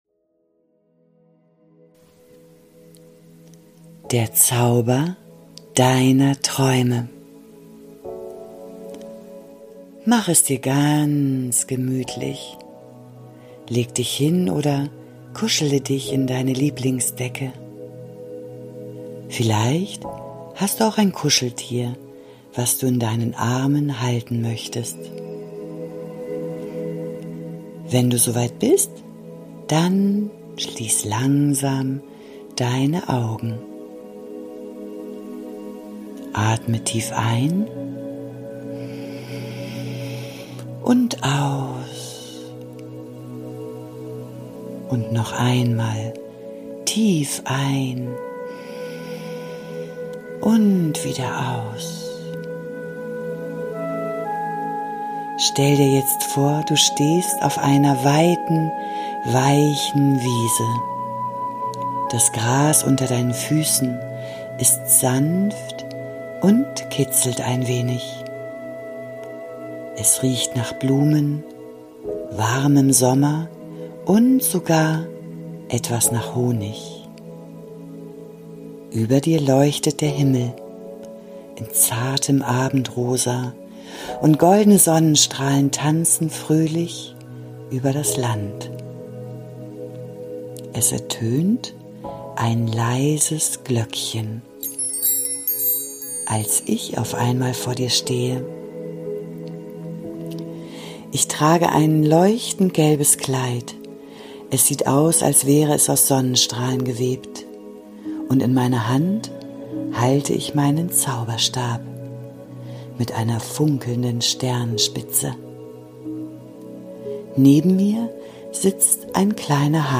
Traumreisen für Kinder Podcast
Eine sanfte Einschlafgeschichte voller Magie und innerer Stärke.